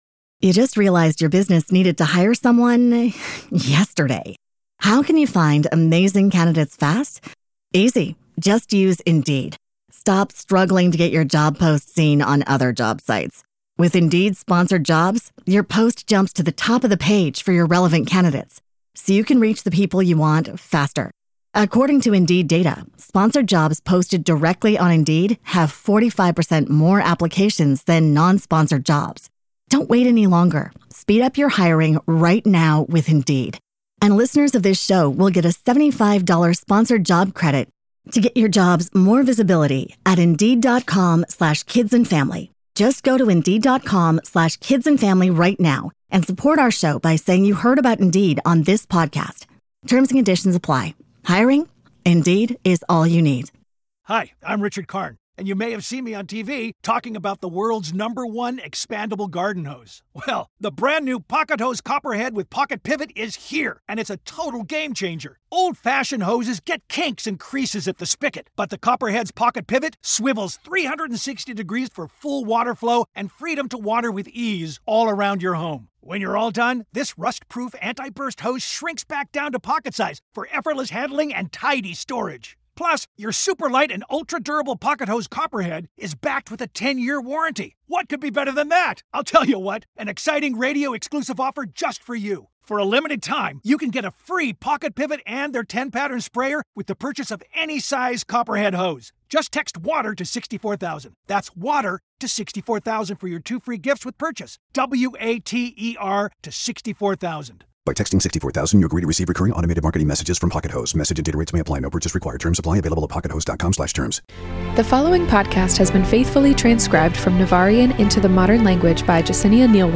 ⚔ Welcome to Season 2 of Between Pages & Friends, a book club podcast hosted by two couples reading through your favorite fantasy series together. In today's episode, we're discussing chapters 1-8 of Fourth Wing by Rebecca Yarros (book one in the Empyrean Series)!